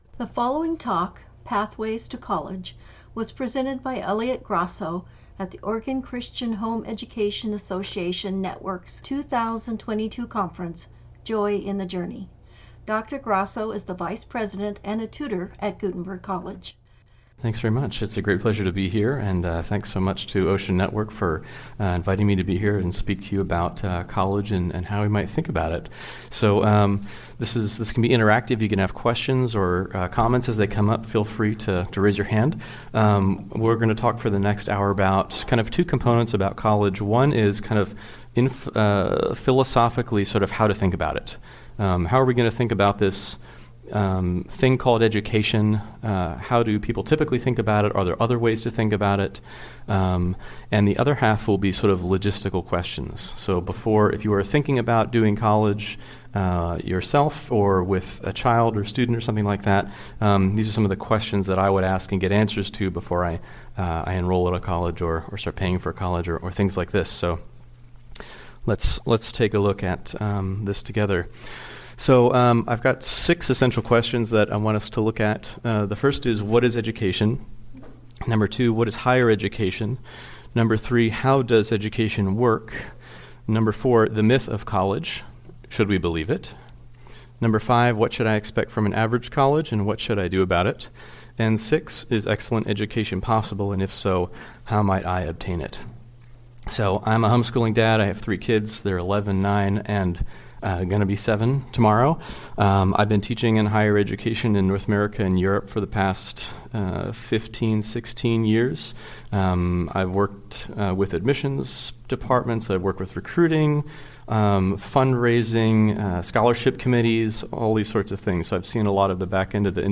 Pathways to College Talk